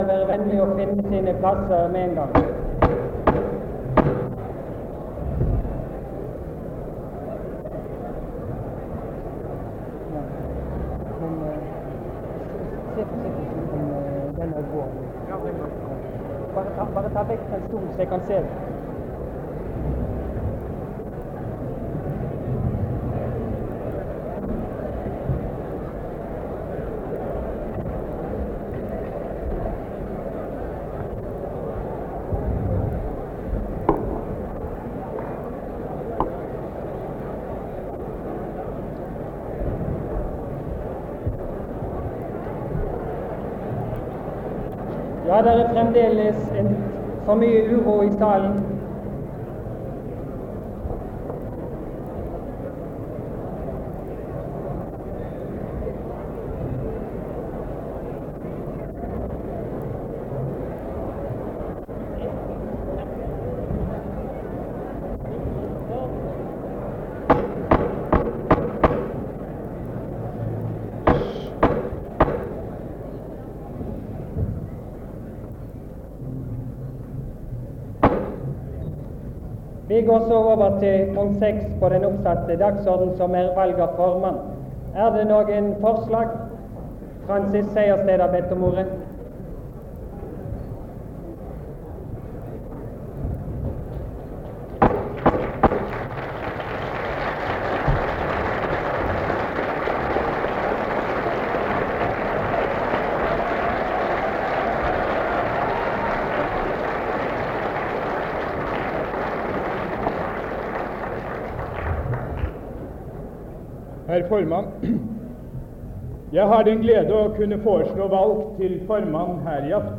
Det Norske Studentersamfund, Generalforsamling, 25.04.1964